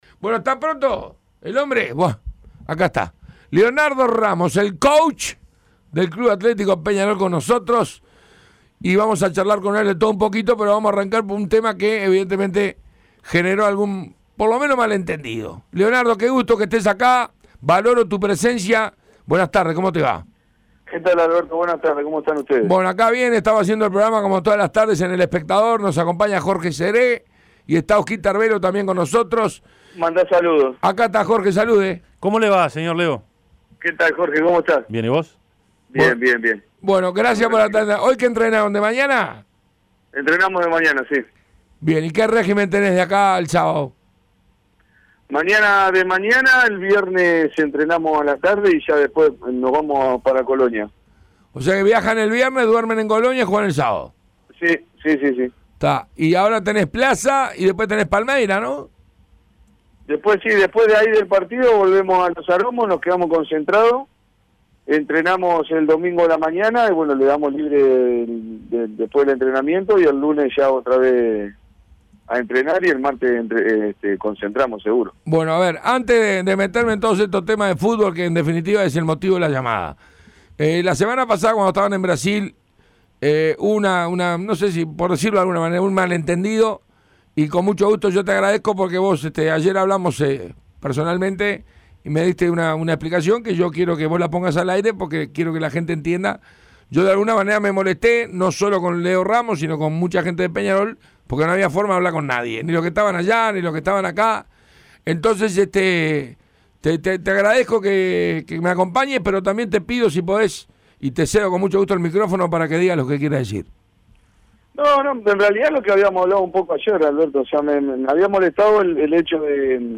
El entrenador de Peñarol, Leonardo Ramos, fue entrevistado por el panel de Tuya y Mía. Aclaró el malentendido con Alberto Sonsol y analizó el presente carbonero sin esquivar ningún tema. El clásico, Palmeiras, el porvenir y mucho más. Entrevista completa.